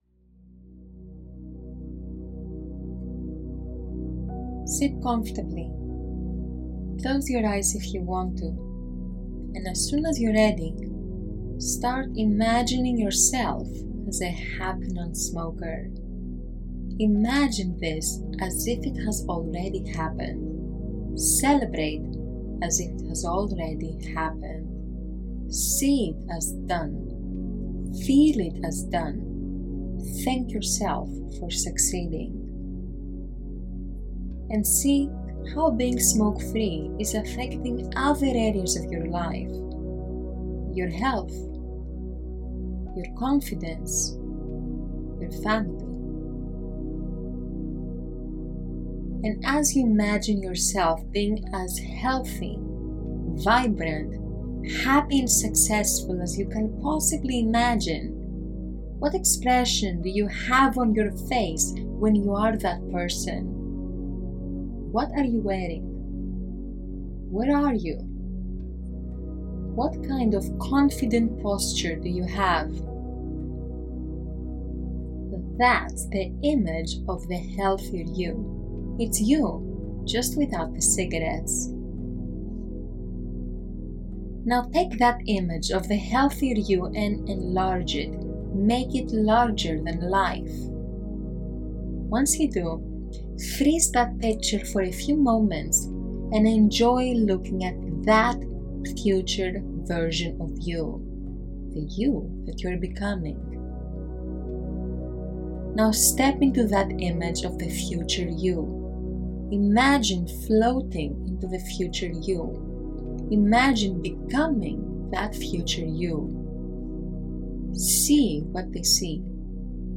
Guided visualization (Audio):
Day+1+-+Guided+Visualization+Audio.m4a